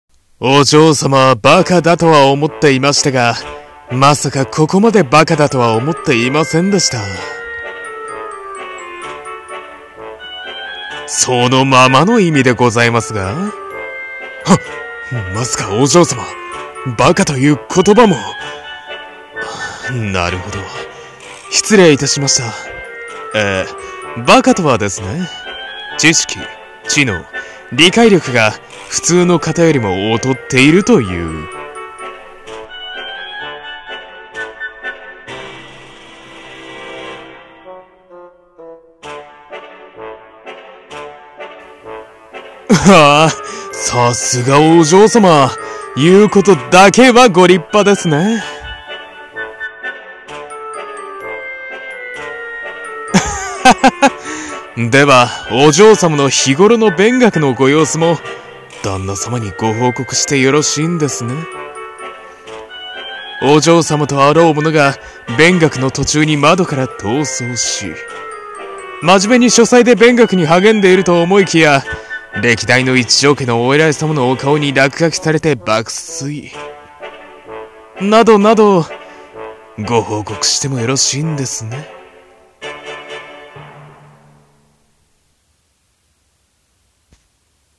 【声劇】 バカなお嬢様【掛け合い】